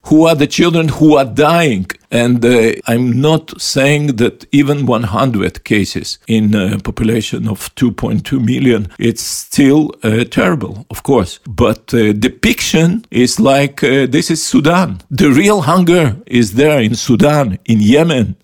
O svemu smo u Intervjuu tjedna Media servisa razgovarali s izraelskim veleposlanikom u Hrvatskoj Garyjem Korenom koji je poručio: "Mi nismo ludi ljudi, ne želimo još desetljeća nasilja; lideri trebaju glasno reći Hamasu da je dosta!"